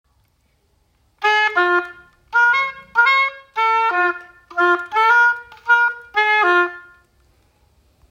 Oboe
Oboe.m4a